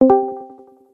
Memes
Discord Join Meme